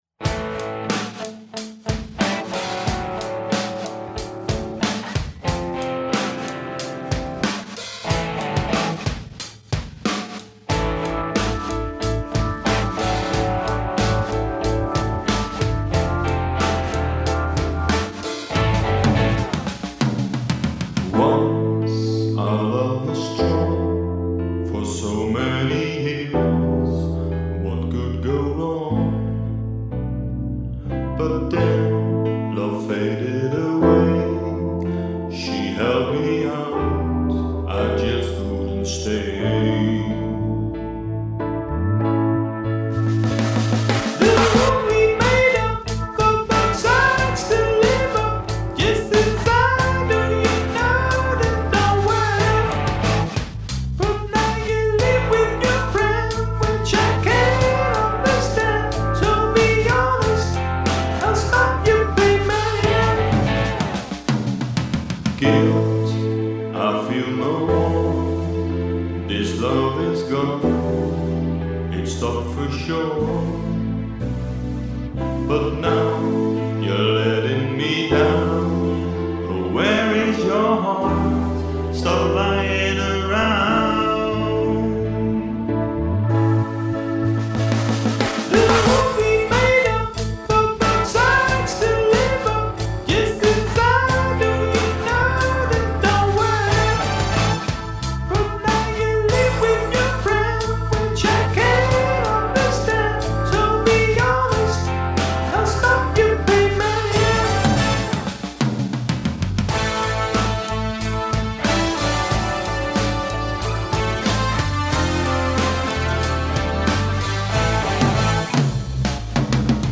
Onderstaand liedje, geschreven/ingespeeld/opgenomen in 2011 en opgepoetst in 2015.
(drums zijn overigens samples)